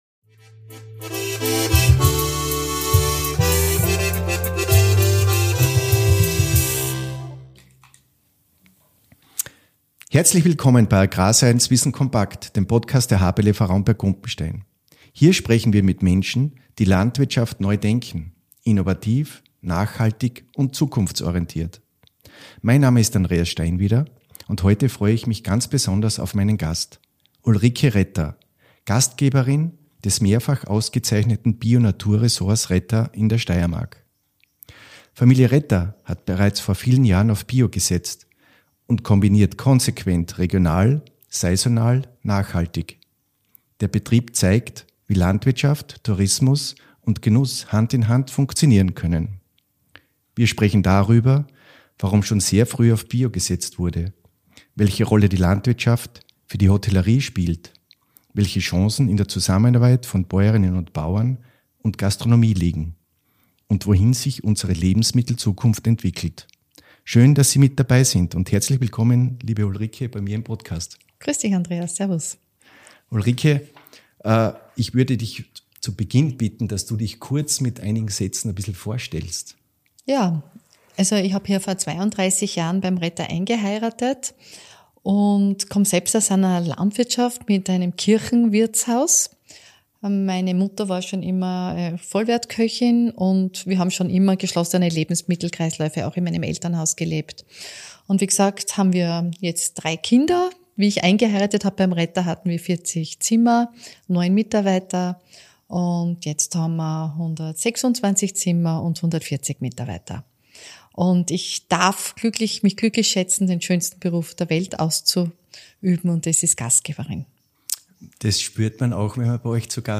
Ein Gespräch über Haltung, Herausforderungen und die eigene Vision – eine persönliche Perspektive, die zum Nachdenken über Landwirtschaft, Genuss und Verantwortung anregt.